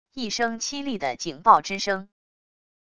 一声凄厉的警报之声wav音频